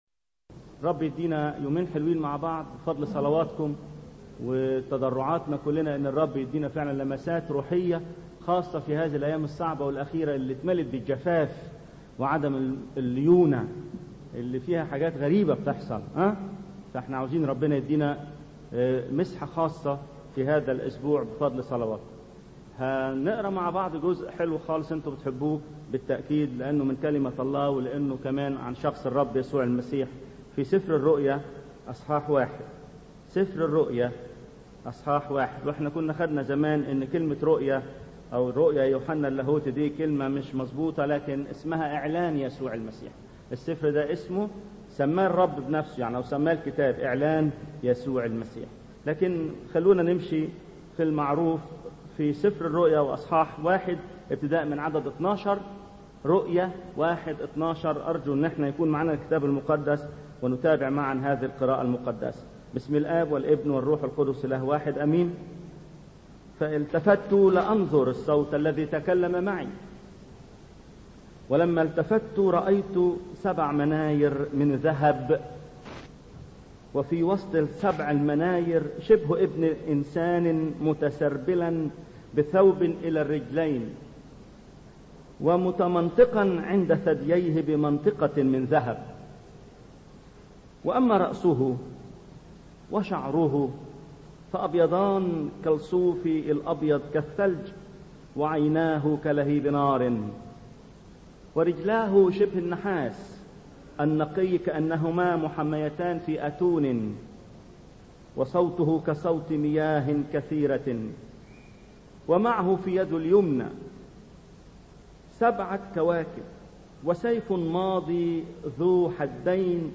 ثلاث عظات عن إعلان يسوع المسيح من سفر الرؤيا الأصحاح الأول.. العظة الأولى – هل رأيت يسوع ؟؟